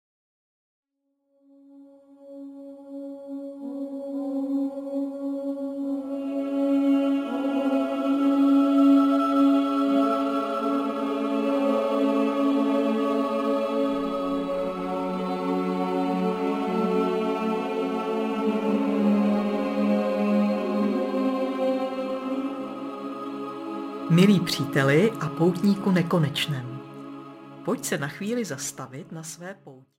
Ukázka z knihy
Vedená meditace TO TICHO TADY TEĎ je ideální pro všechny